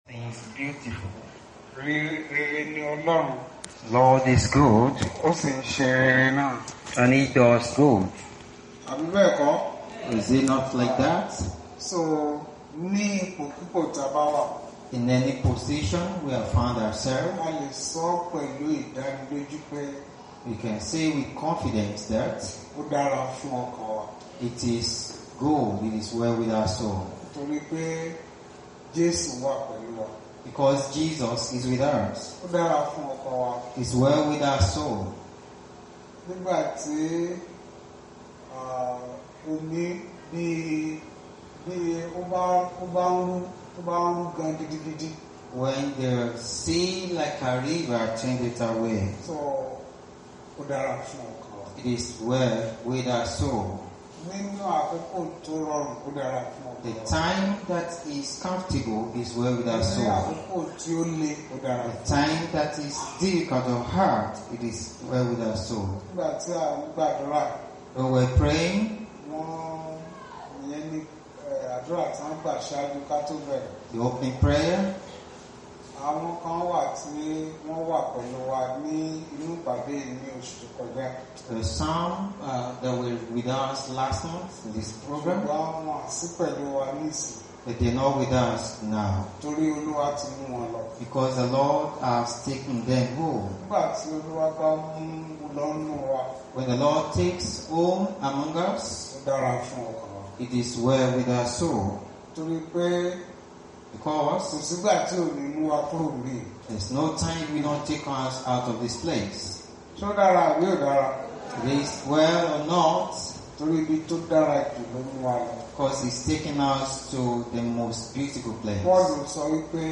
Wholesome Words Bible Class Passage: Galatians 6:14, 17